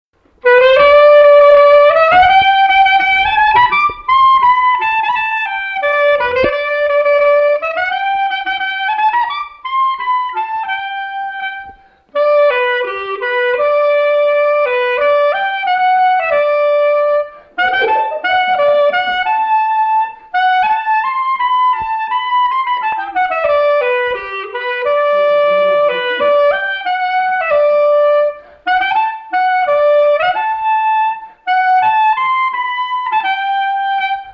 Résumé instrumental
circonstance : fiançaille, noce
Pièce musicale inédite